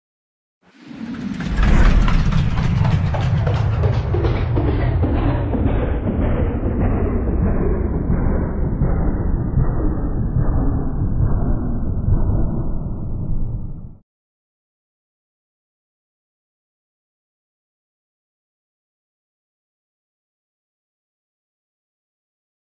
دانلود آهنگ صحنه آهسته 1 از افکت صوتی طبیعت و محیط
جلوه های صوتی
برچسب: دانلود آهنگ های افکت صوتی طبیعت و محیط دانلود آلبوم صدای صحنه آهسته یا اسلو موشن از افکت صوتی طبیعت و محیط